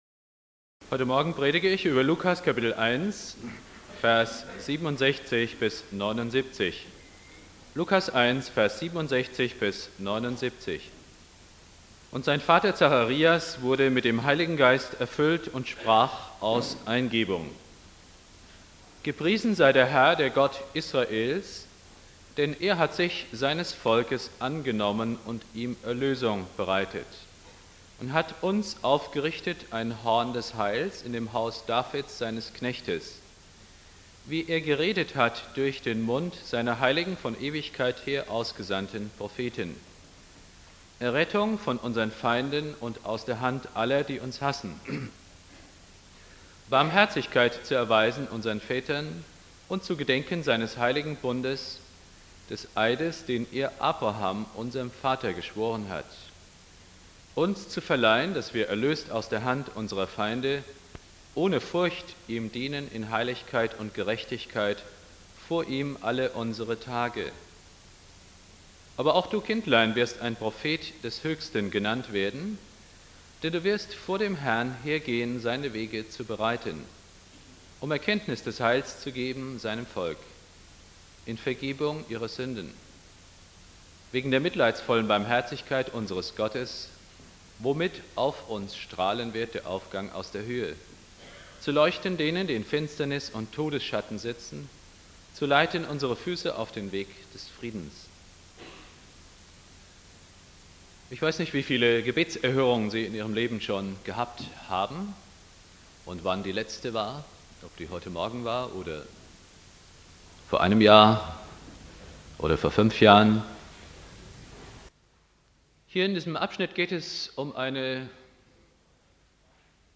Predigt
1.Advent